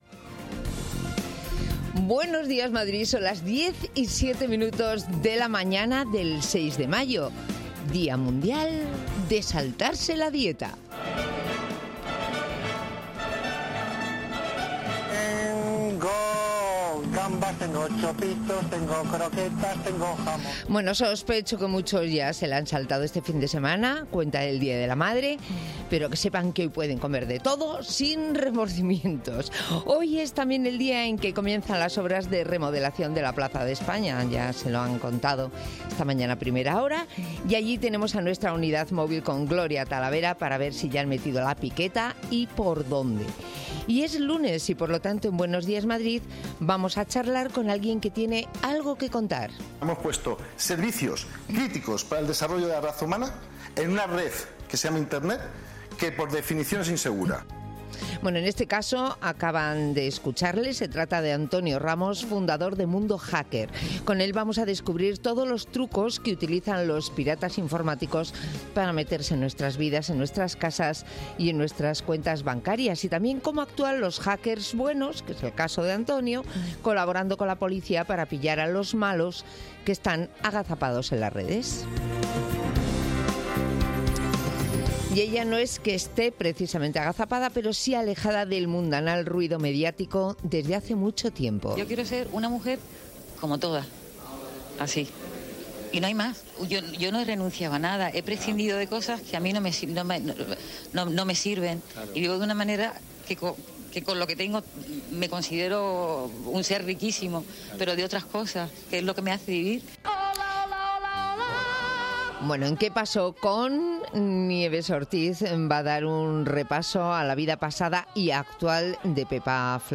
Tras las vallas que rodean la Plaza de España se escucha ya el ruido del comienzo de las obras. Arranca la reforma que multiplicará por 3 la superficie de una plaza que va a cambiar por dentro y por fuera.